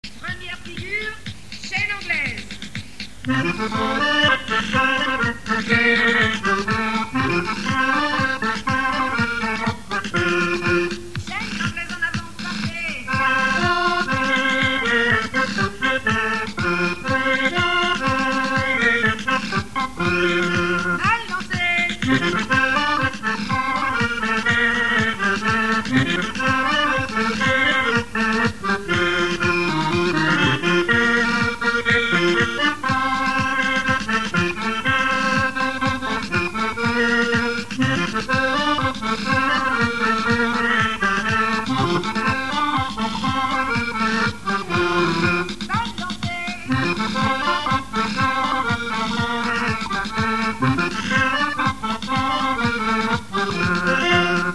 danse : quadrille : chaîne anglaise
Enquête Arexcpo en Vendée-Pays Sud-Vendée
Pièce musicale inédite